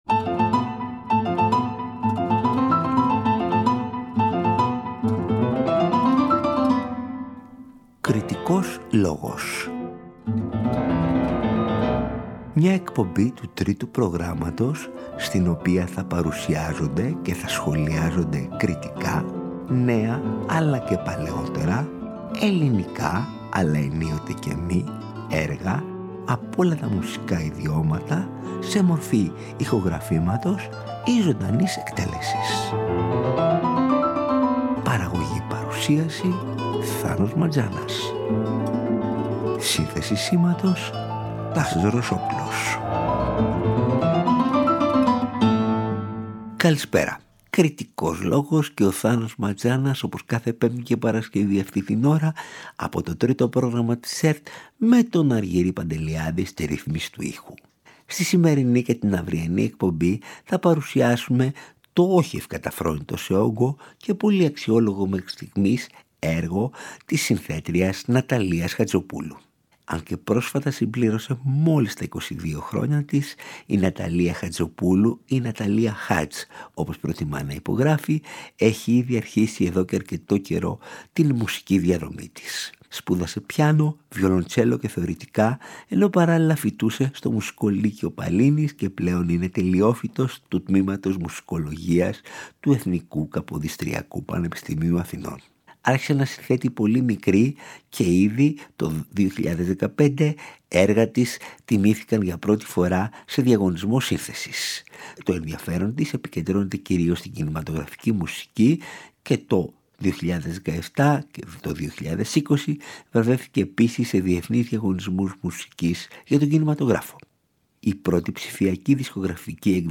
για ψηφιακή ορχήστρα και σόλο βιολοντσέλο
για κουαρτέτο εγχόρδων